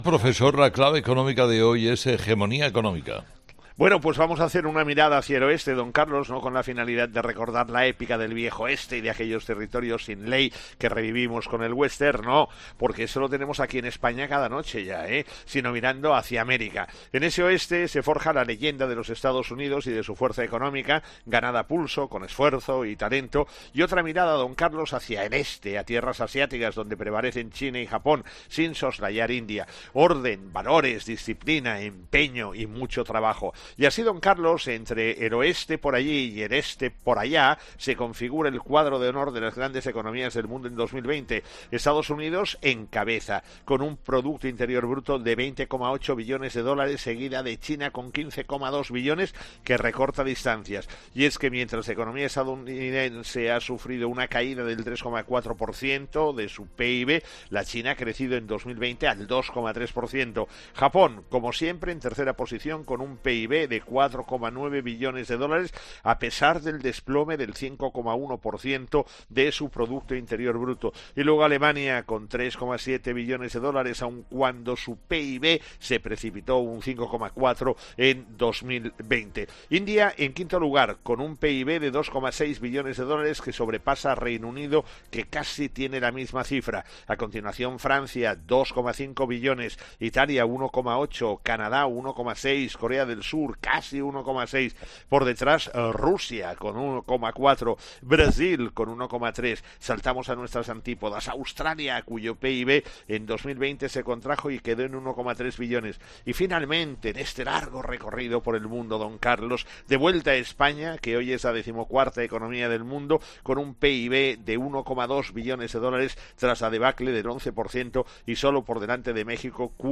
El profesor José María Gay de Liébana analiza en 'Herrera en COPE’ las claves económicas del día.